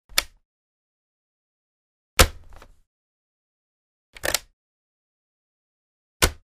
Звук открывающейся и закрывающейся крышки ноутбука